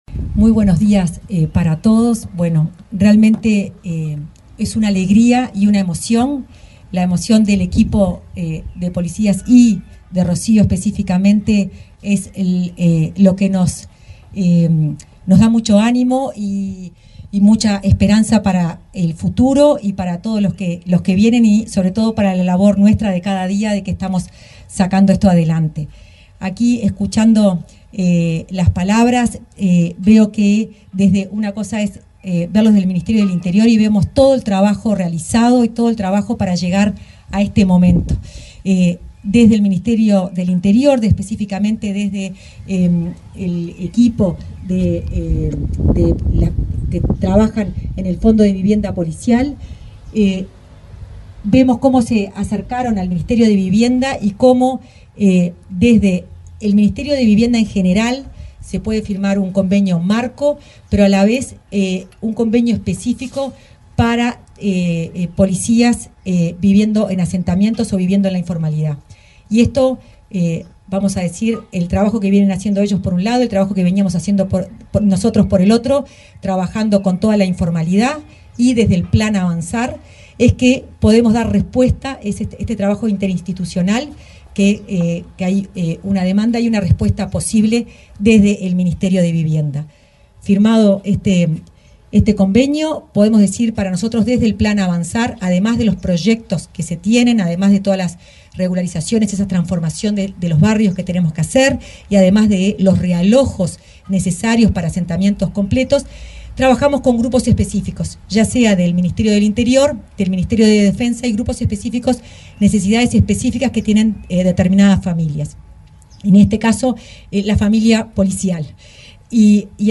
Palabra de autoridades en acto de entrega de vivienda